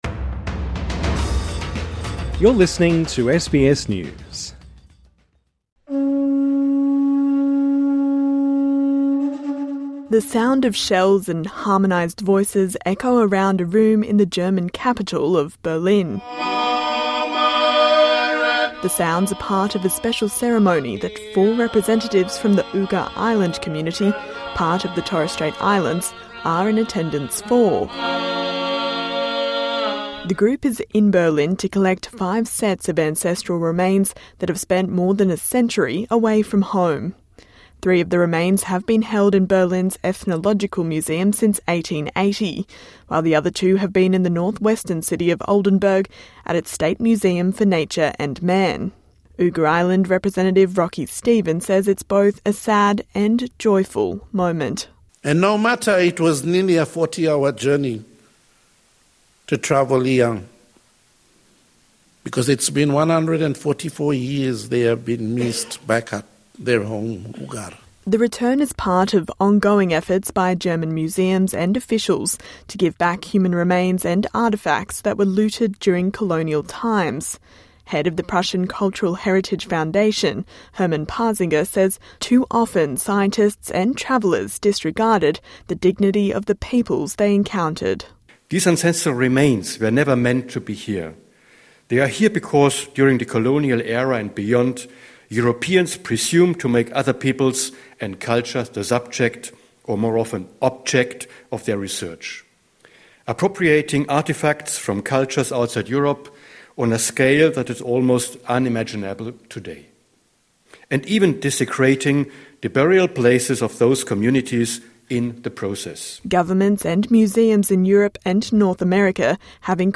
TRANSCRIPT: The sound of shells and harmonised voices echo around a room in the German capital of Berlin. The sounds are part of a special ceremony that four representatives from the Ugar Island community - part of the Torres Strait Islands - are in attendance for.